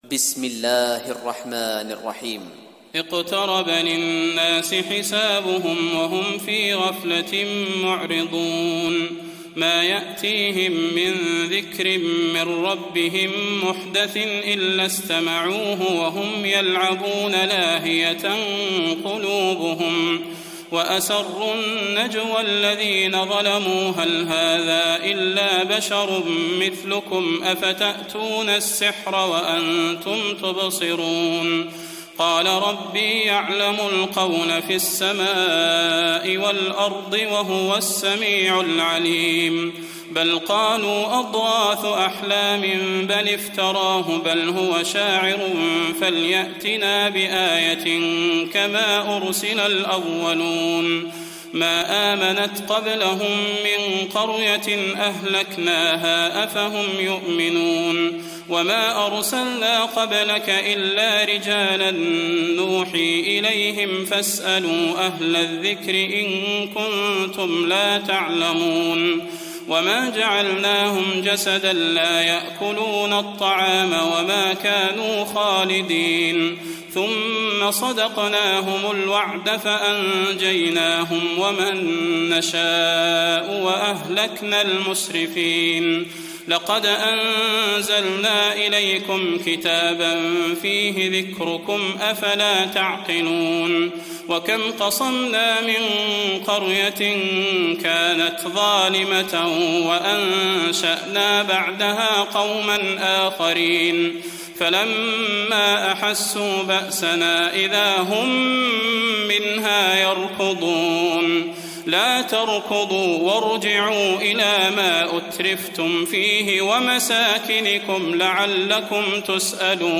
تراويح الليلة السادسة عشر رمضان 1419هـ سورة الأنبياء كاملة Taraweeh 16th night Ramadan 1419H from Surah Al-Anbiyaa > تراويح الحرم النبوي عام 1419 🕌 > التراويح - تلاوات الحرمين